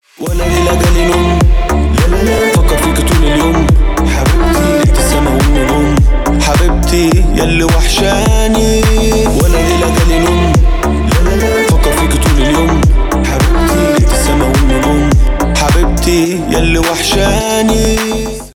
клубные
восточные , club house , deep house